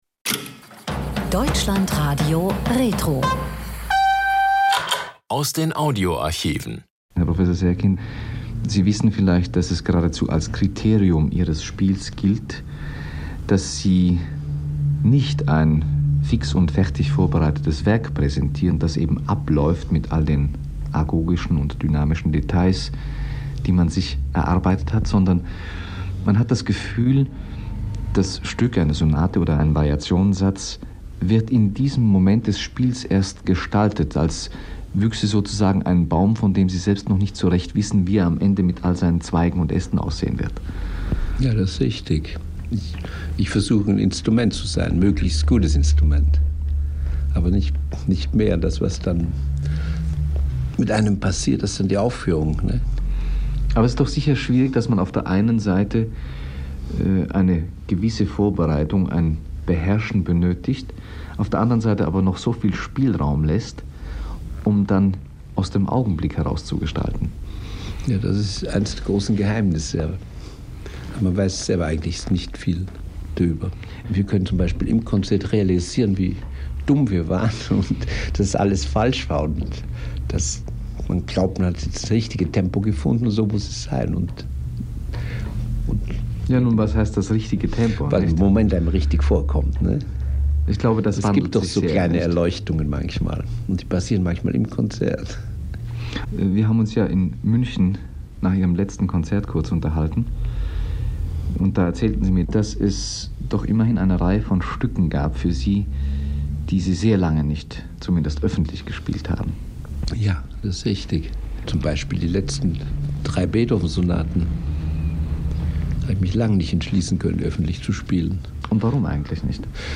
Der Pianist Rudolf Serkin im Dlf-Gespräch